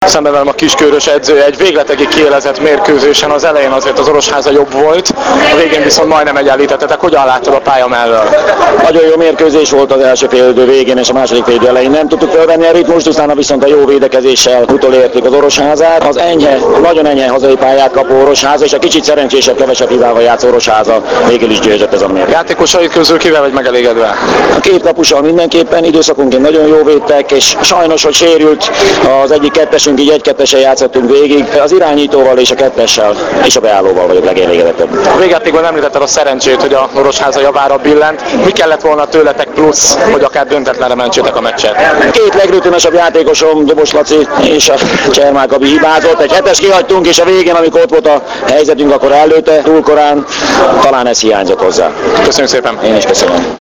Az interjúk mp3 formátumban hallgathatók meg.